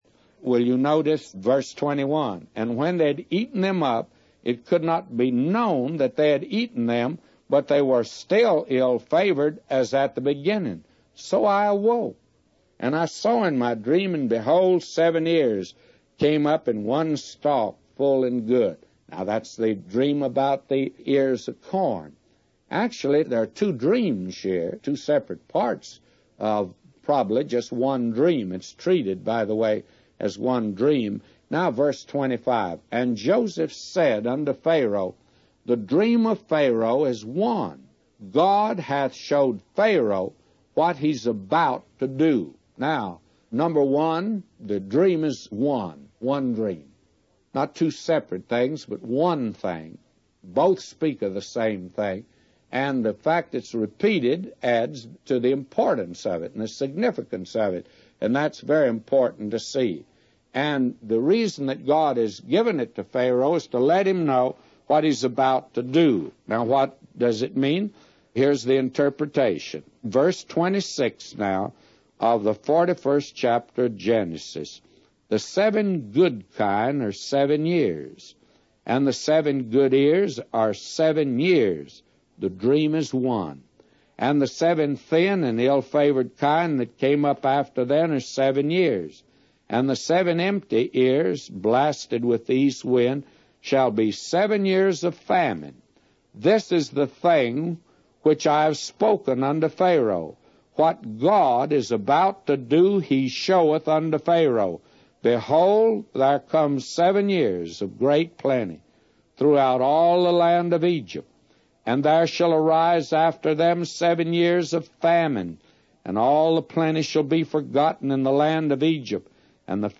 A Commentary By J Vernon MCgee For Genesis 41:21-999